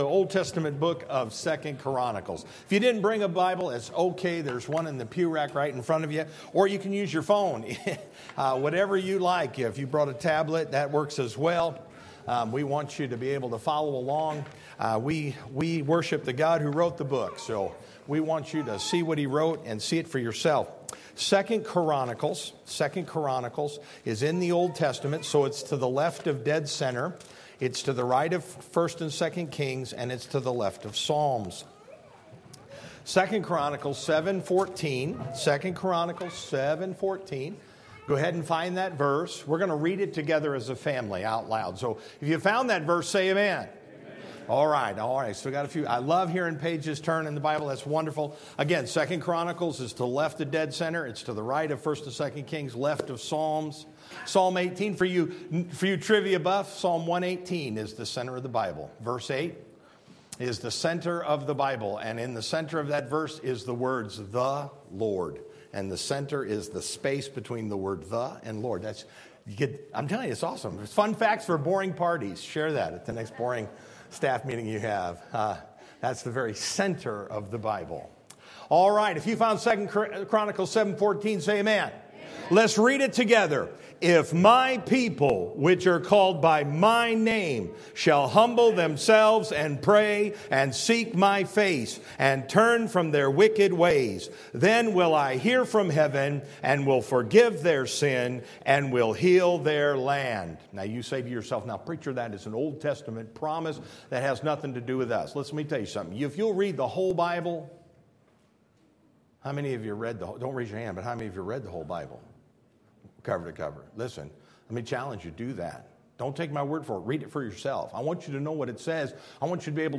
Sermon Audio :: First Baptist Church of Kingstowne